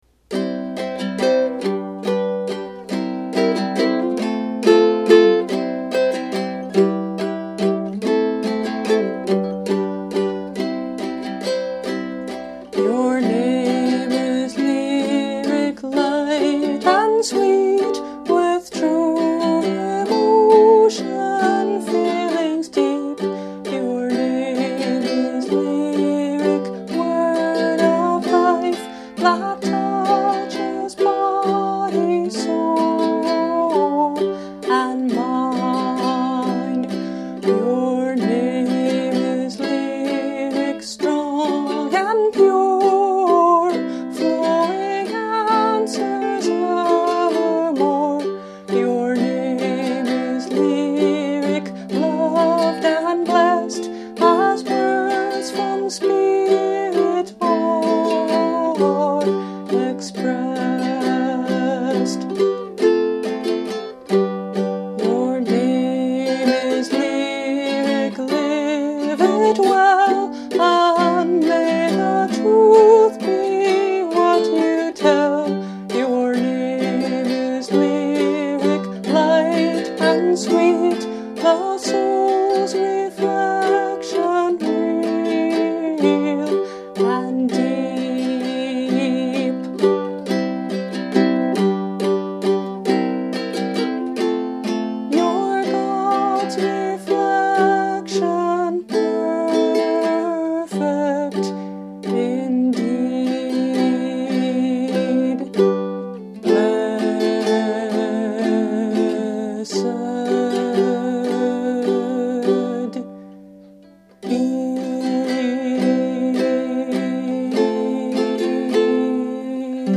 Instrument: Lyric – Mainland red cedar tenor ukulele